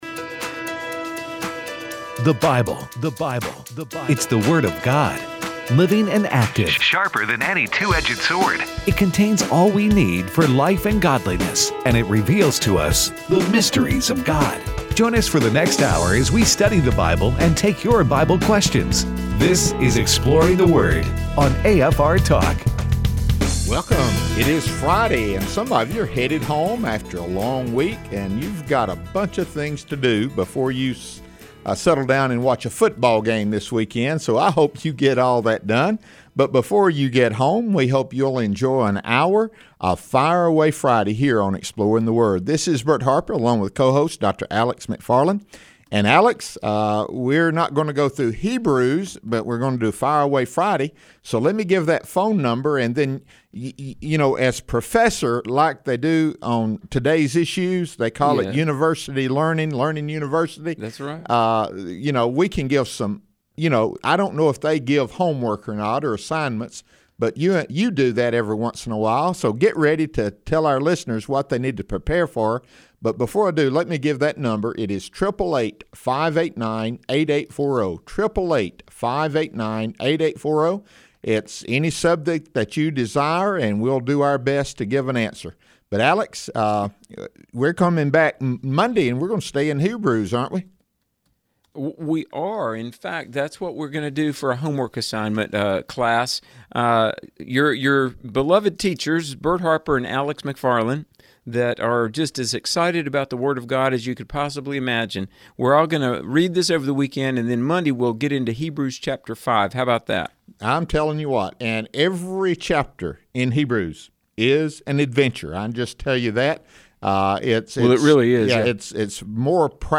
takes your phone calls throughout the show.